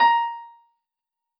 piano-ff-62.wav